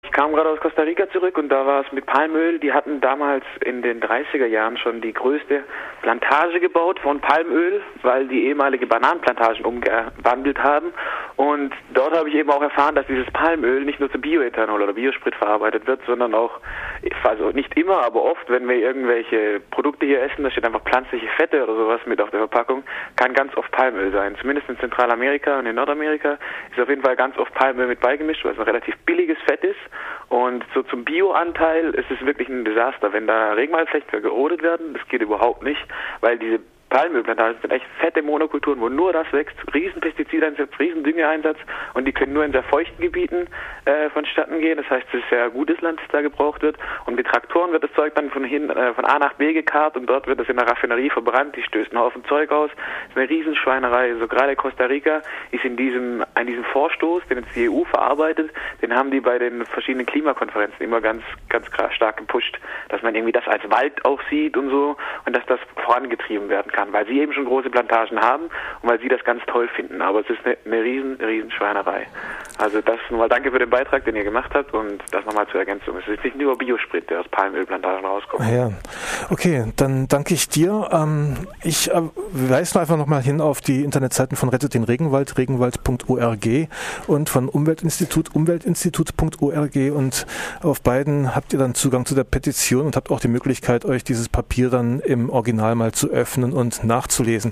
Ergänzung eines Zuhörers, der kürzlich aus Costa Rica zurückgekehrt ist: